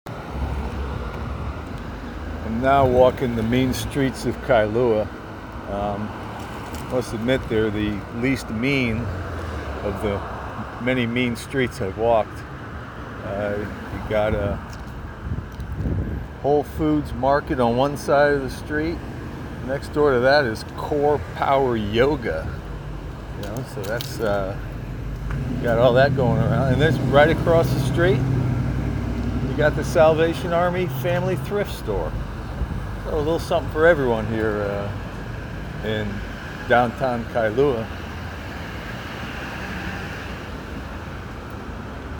Whole Foods in Kailua…fancy store meets fancy beach town…a marriage made in heaven